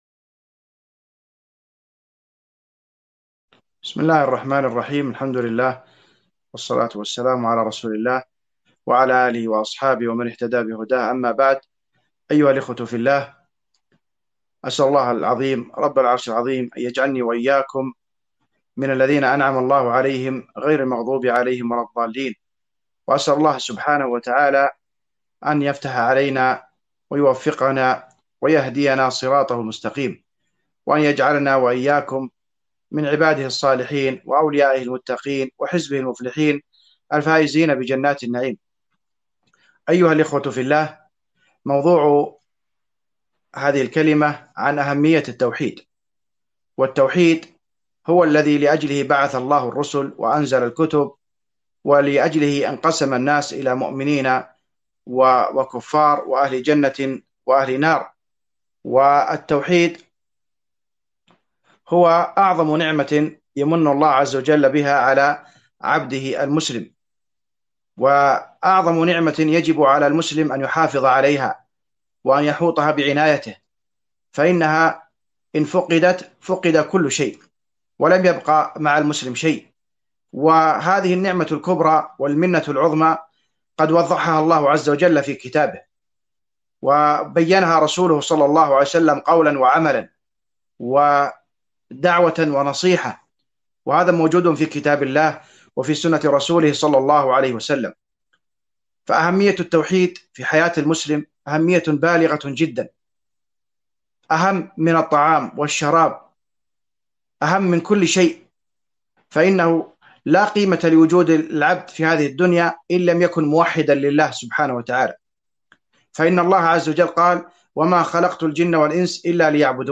محاضرة بعنوان أهمية التوحيد